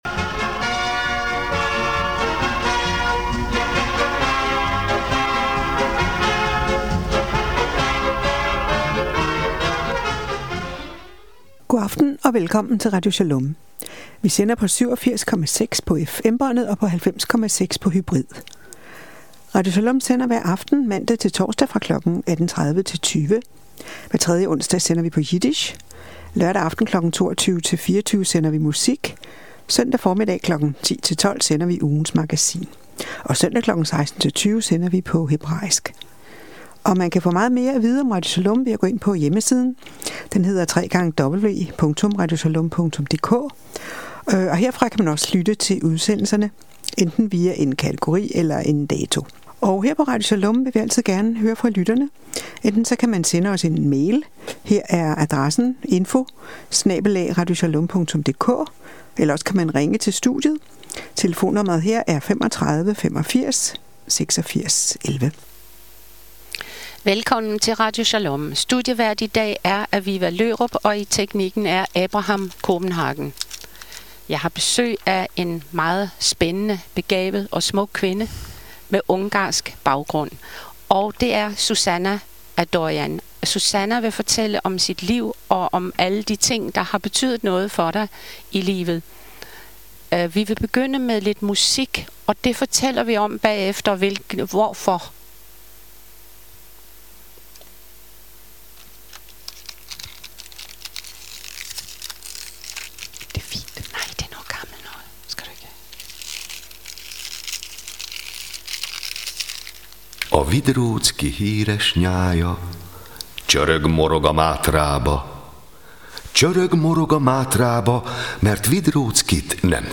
Beskrivelse:Interview